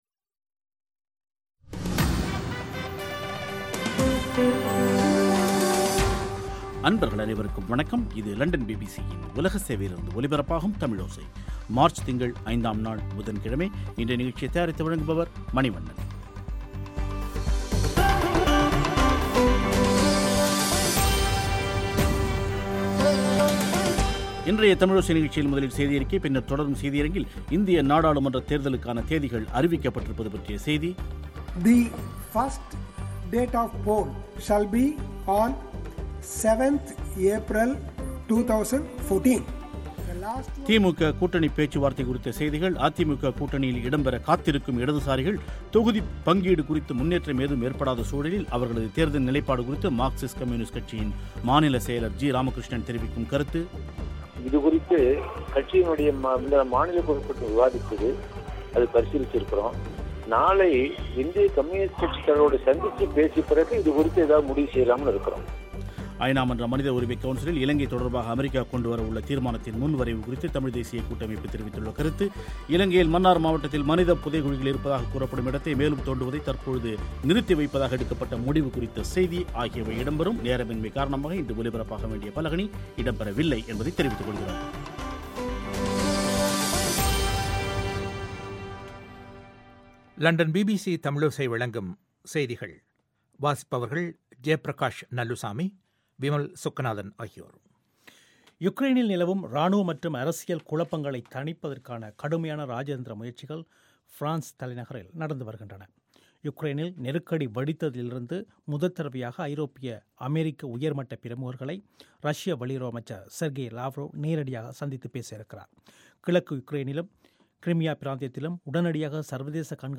இன்றைய தமிழோசை நிகழ்ச்சியில்,முதலில் செய்தி அறிக்கை, பின்னர் தொடரும் செய்தி அரங்கில் *இந்திய நாடாளுமன்றத்தேர்தலுக்கான தேதிகள் அறிவிக்கப்பட்டிருப்பது பற்றிய செய்தி,